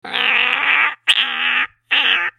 Weird Bird Sound Button - Free Download & Play